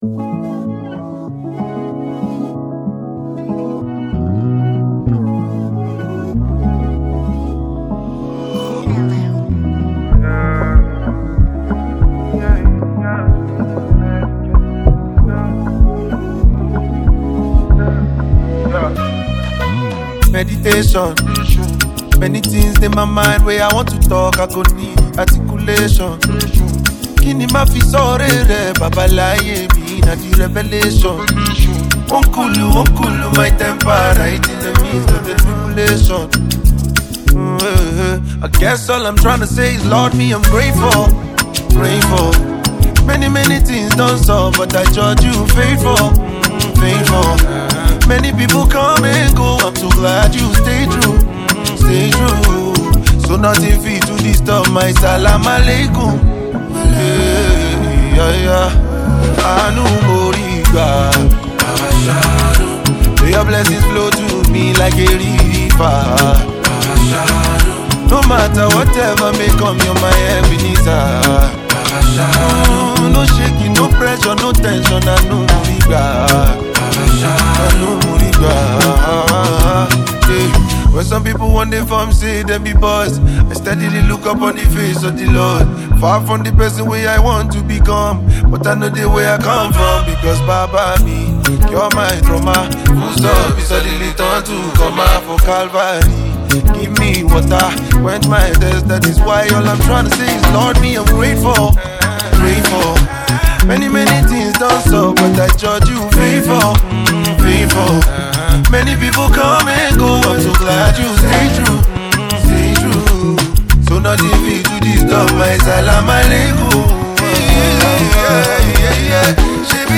Afro Gospel Music
soul-stirring new single
With its uplifting beats and inspiring lyrics